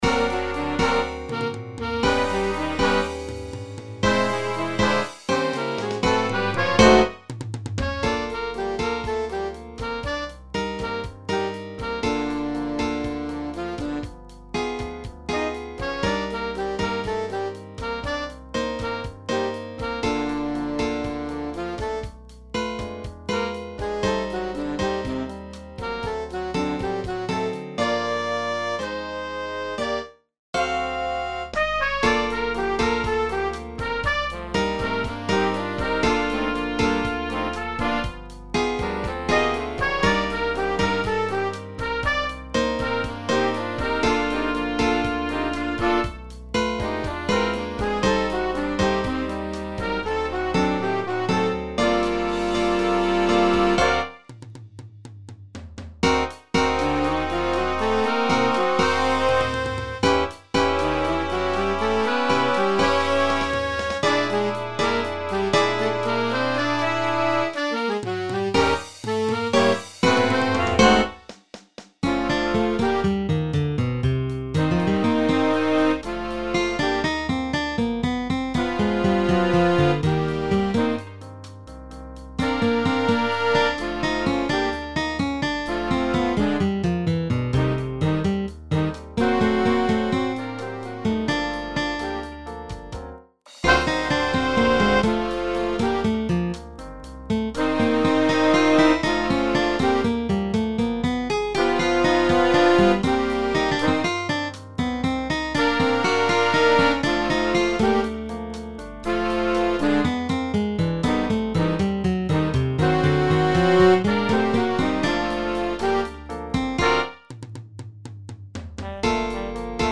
Voicing: Combo